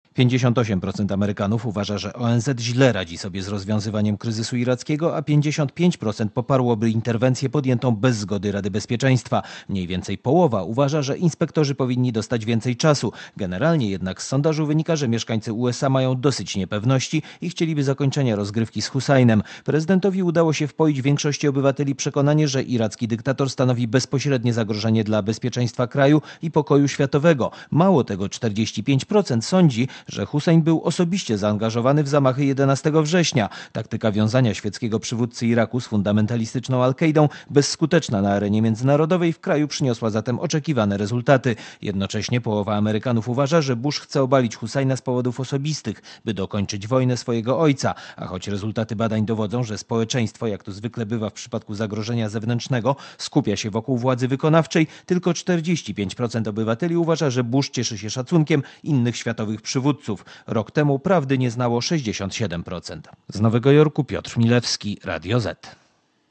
(RadioZet) Źródło: (RadioZet) Korespondencja z USA (517Kb)